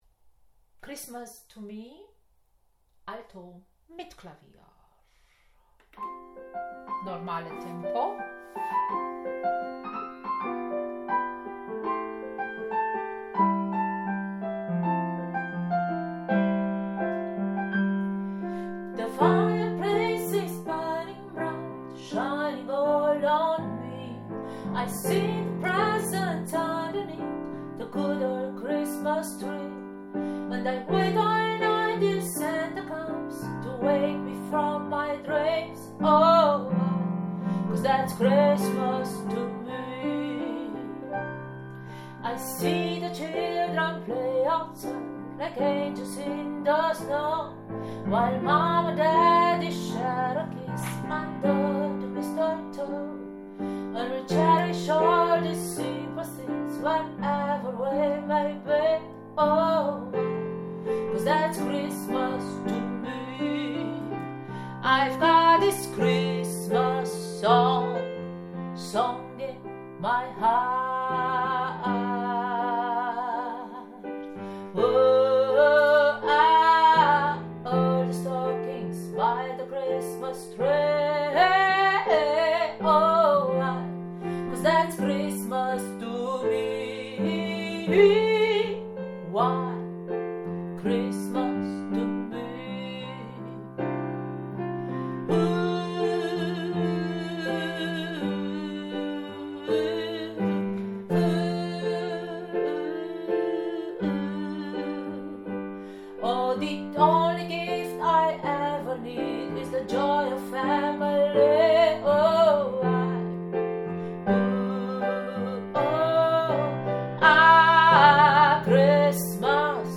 That´s-Christmas-to-me-AltoKlavier.mp3